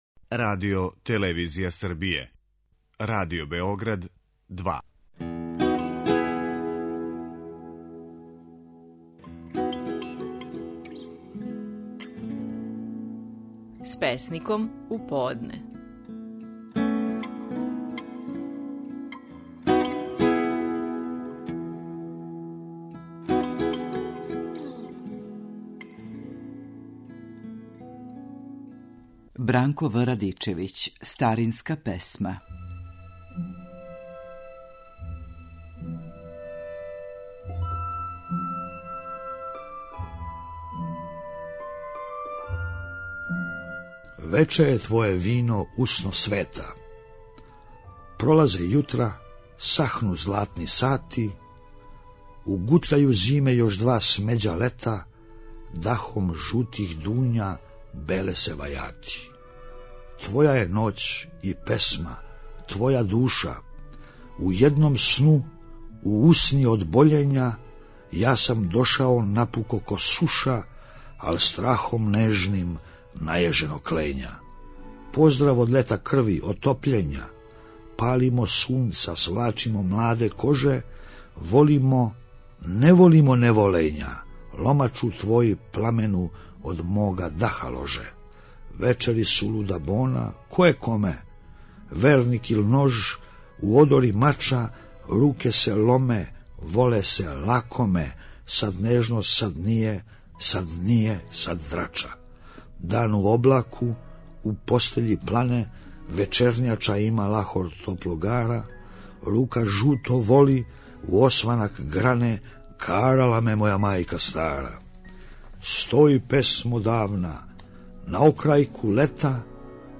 Наши најпознатији песници говоре своје стихове
Бранко В. Радичевић говори "Старинску песму".